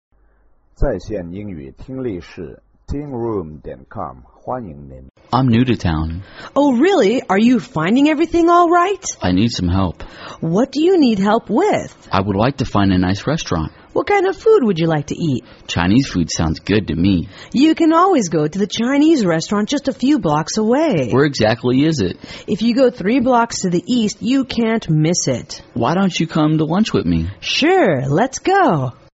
情景英语对话：Where to Find Places to Eat(3) 听力文件下载—在线英语听力室